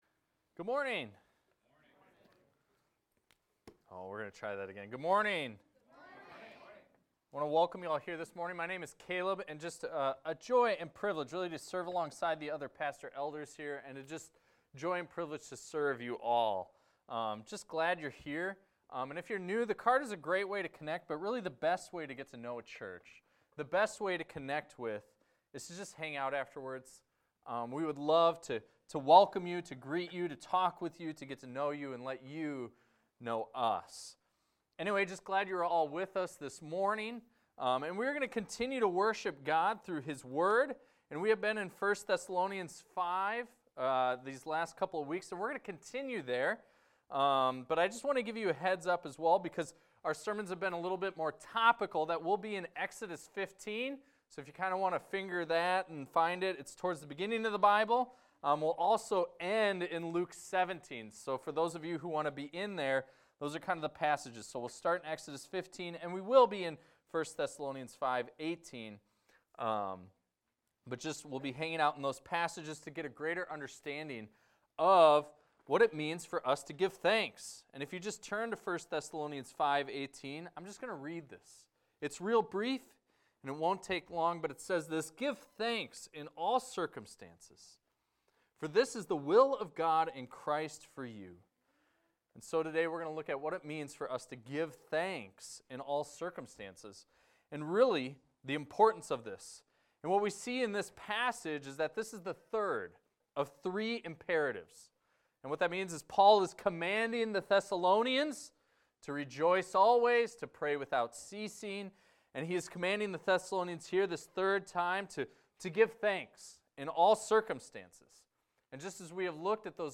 This is a recording of a sermon titled, "Give Thanks."